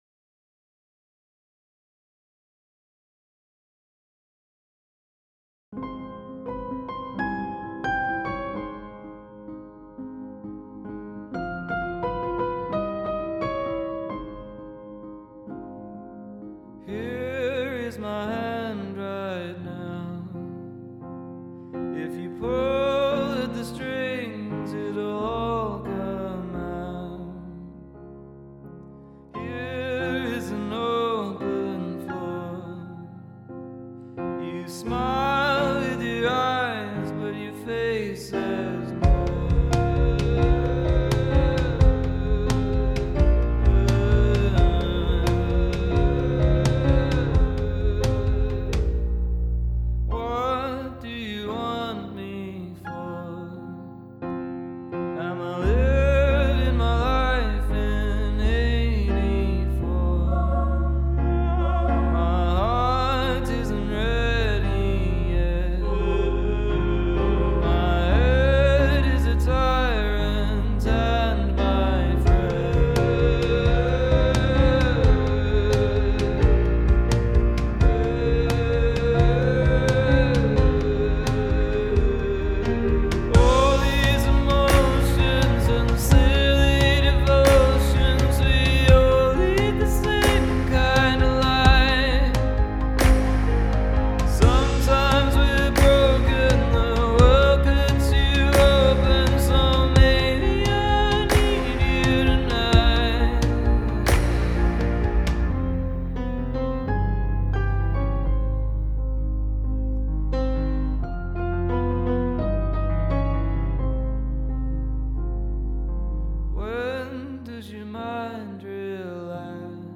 Mix
Lead Mix Engineer